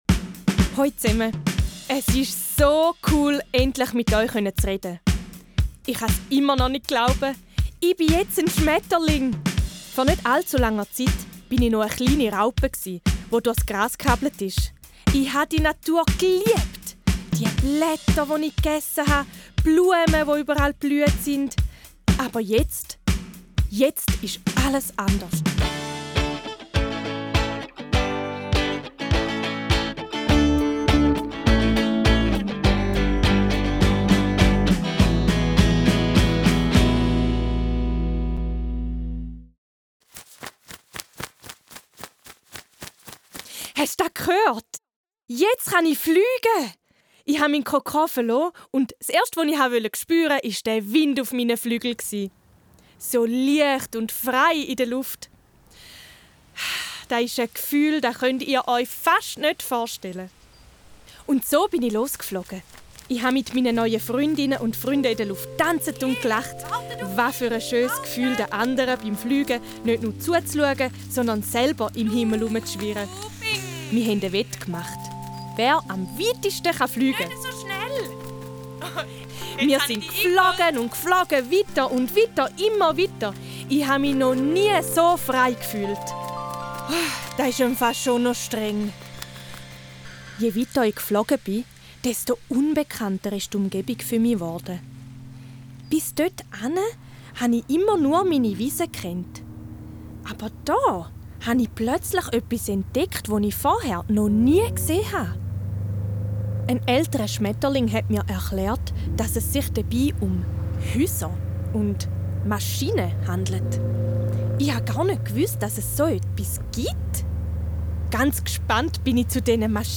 LA Challenge Luft In der vierten Challenge erzählt Schmetterling Orion euch, was er an der der Insektenkonferenz ansprechen möchte. Startet mit dem Hörspiel: Hört euch das Hörspiel an, um mehr über die Bedeutung der Luft und die Probleme der Luftverschmutzung zu erfahren.